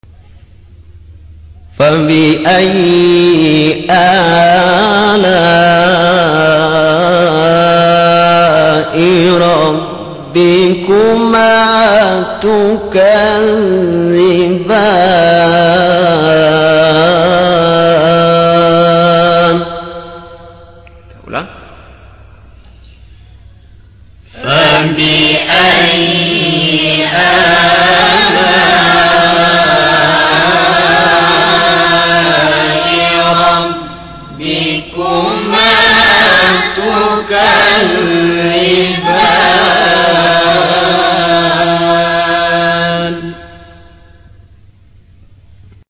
حجاز-اصلی-قرار3.mp3